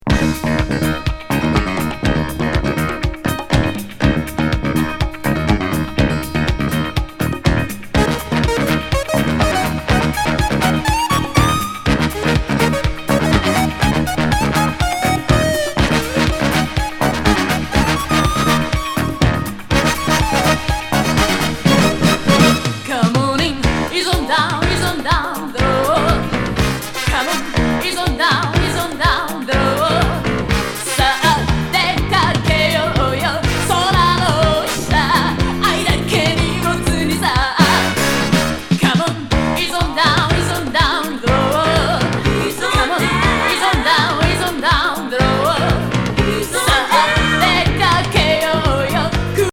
宝塚ブロードウェイ・ファンキー・ディスコ歌謡!